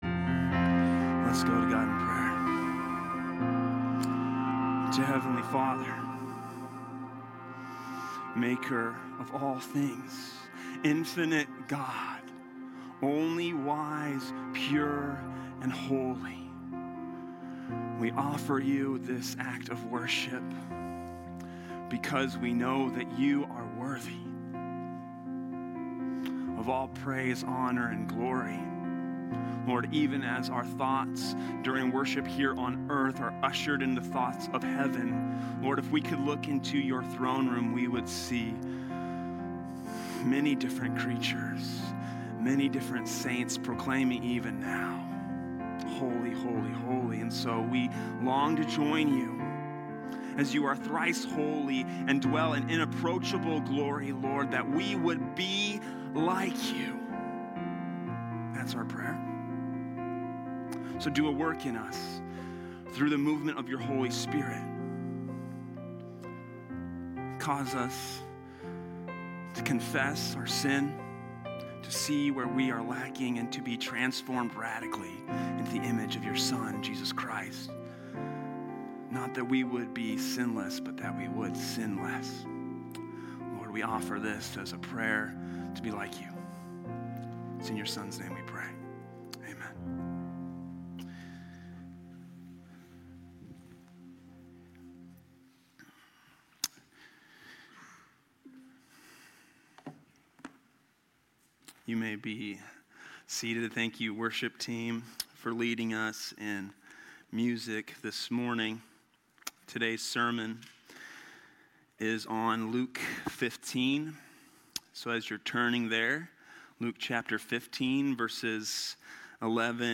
From Series: "Topical Sermons"